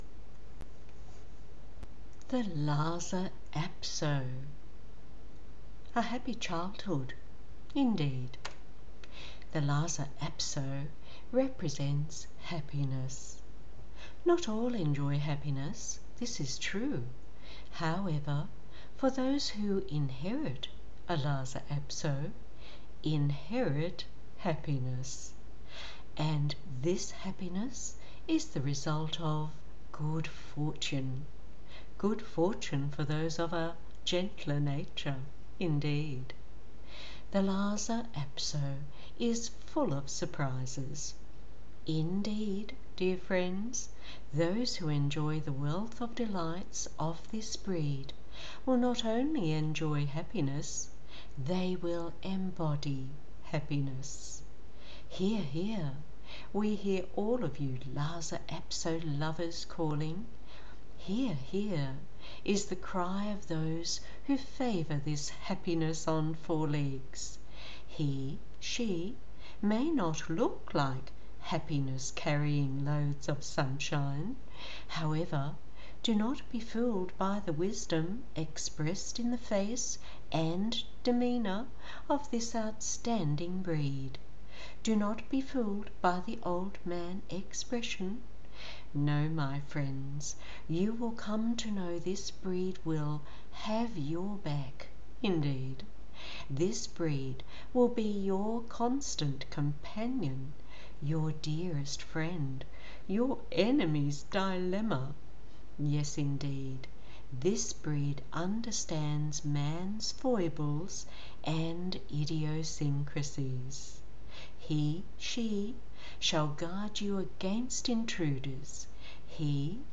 Oh yes, that is my Aussie drawl you were listening to.